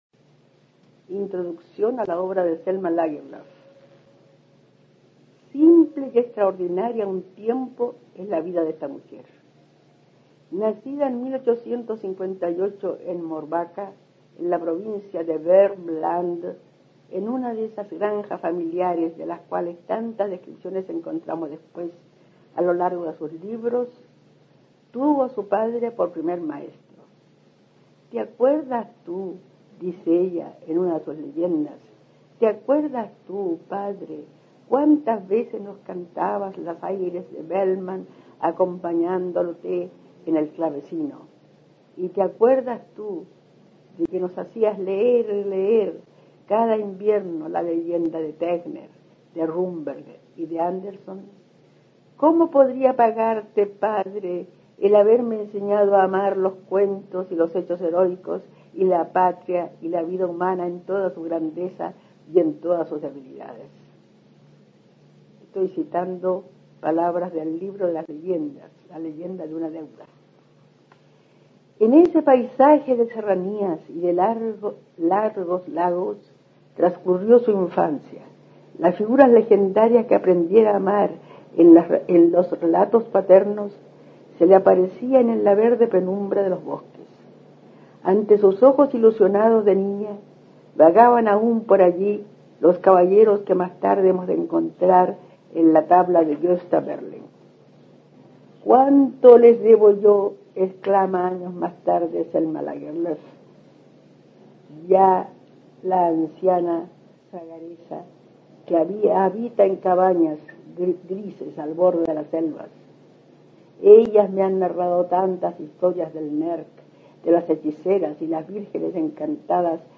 Aquí se puede escuchar a la destacada profesora chilena Amanda Labarca (1886-1975) leyendo un fragmento de su ensayo Introducción a la obra de Selma Lagerlöf, en el que hace una semblanza de la escritora sueca, con especial énfasis en sus primeros años de formación.